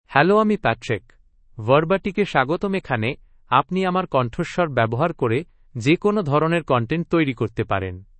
Patrick — Male Bengali AI voice
Patrick is a male AI voice for Bengali (India).
Voice: PatrickGender: MaleLanguage: Bengali (India)ID: patrick-bn-in
Voice sample
Listen to Patrick's male Bengali voice.
Patrick delivers clear pronunciation with authentic India Bengali intonation, making your content sound professionally produced.